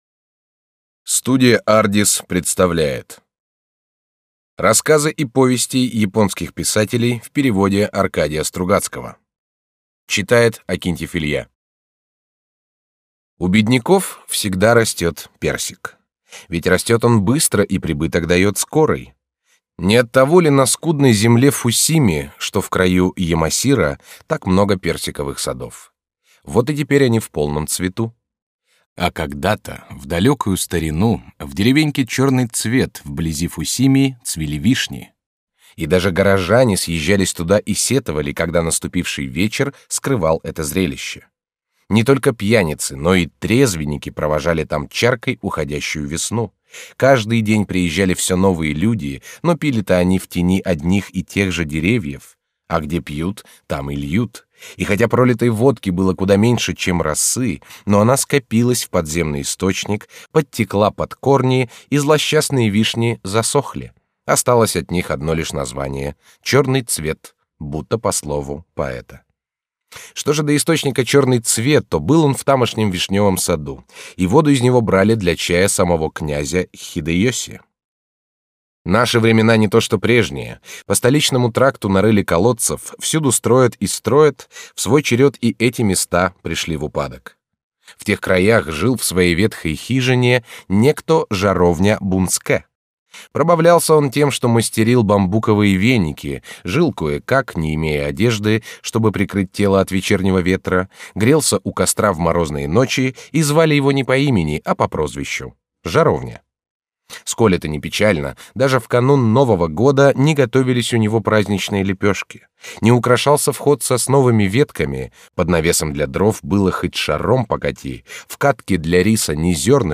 Аудиокнига Рассказы и повести японских писателей в переводе Аркадия Стругацкого | Библиотека аудиокниг
Прослушать и бесплатно скачать фрагмент аудиокниги